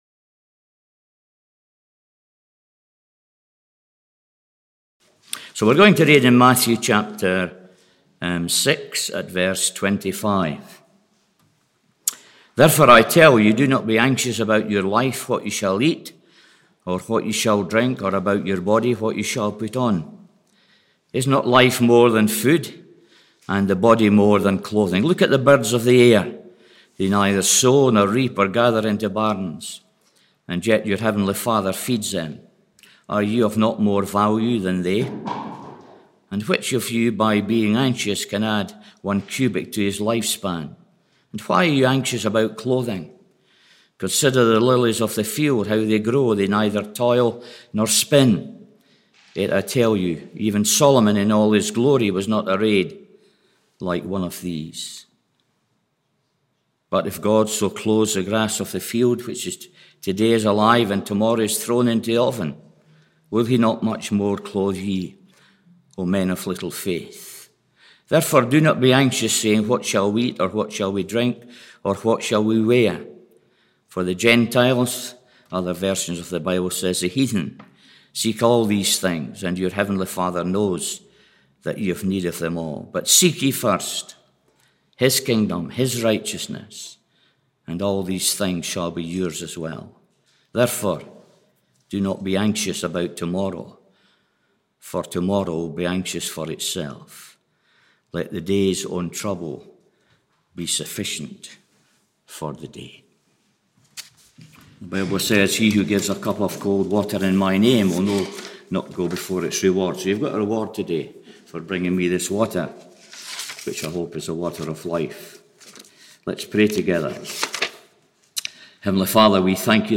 Morning Service 9th May 2021 – Cowdenbeath Baptist Church